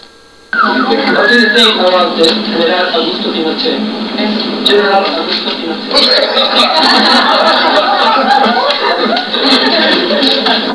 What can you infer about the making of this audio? Here you´ll find many pictures of the band in Santiago and a couple of sounds from the press conference they gave when they arrived.